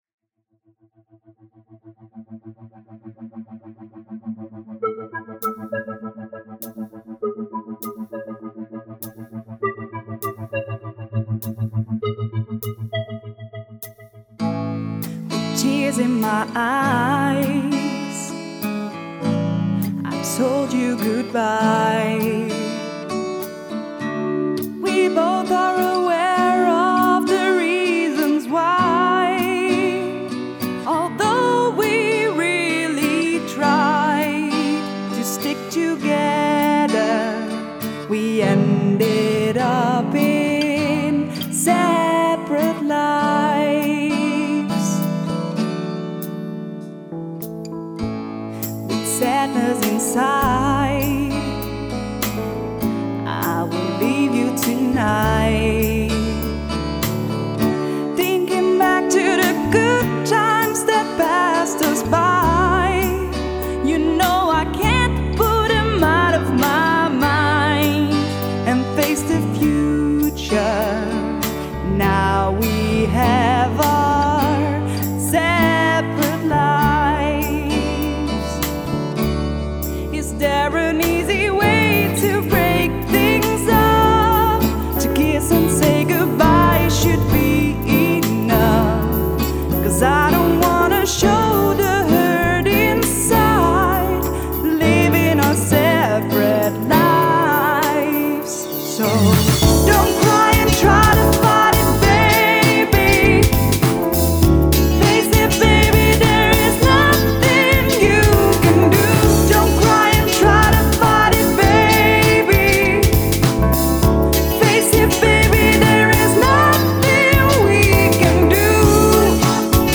is een ambivalent duet